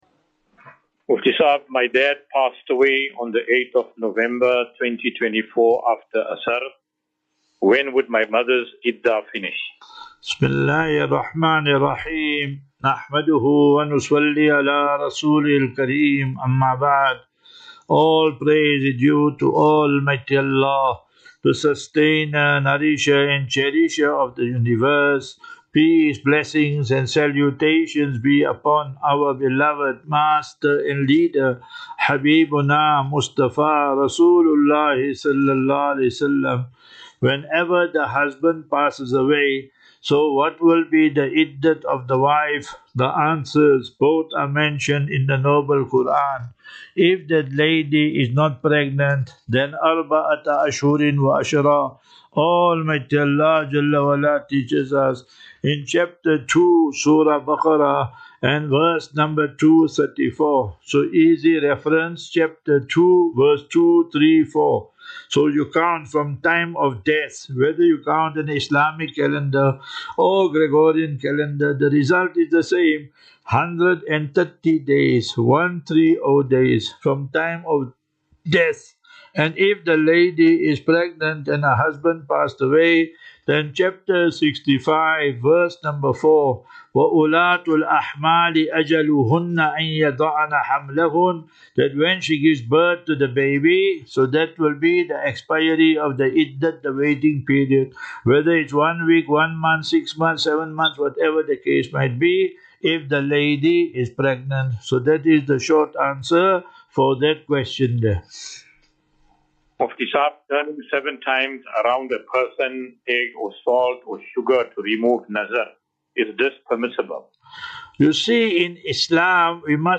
View Promo Continue Install As Safinatu Ilal Jannah Naseeha and Q and A 11 Mar 11 March 2025.